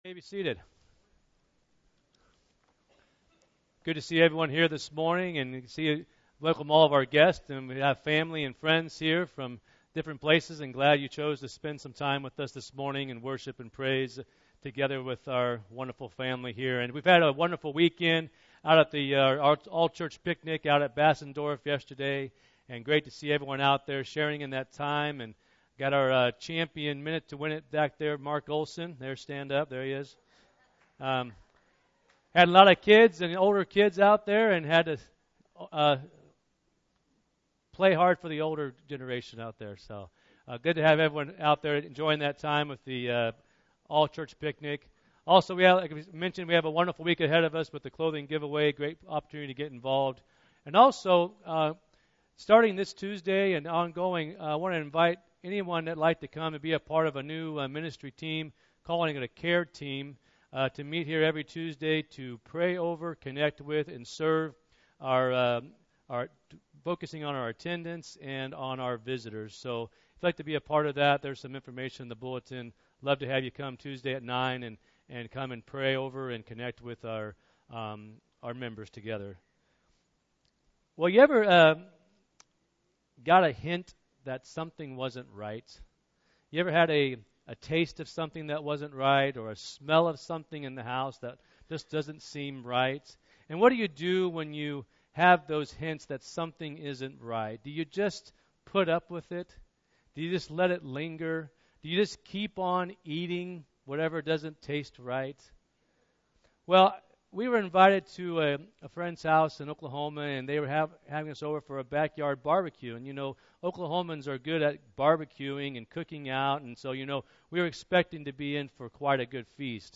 Free Sermons in MP3